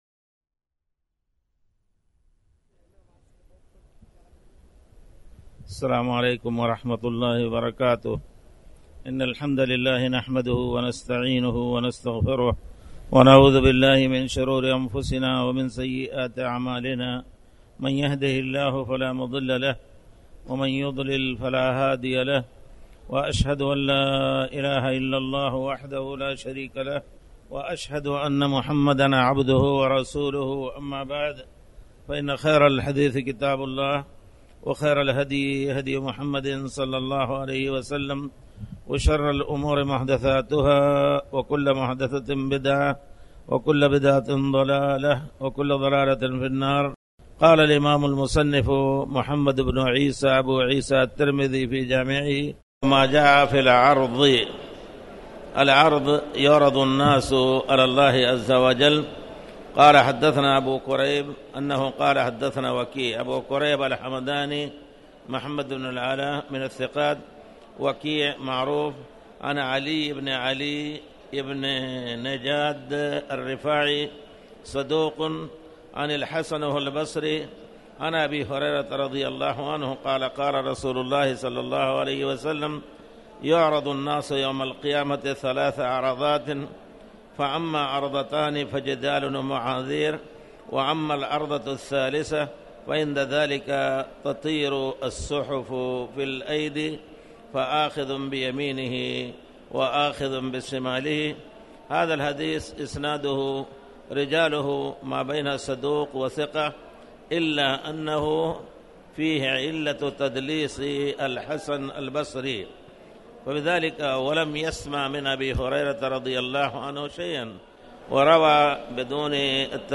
تاريخ النشر ٦ جمادى الآخرة ١٤٣٩ هـ المكان: المسجد الحرام الشيخ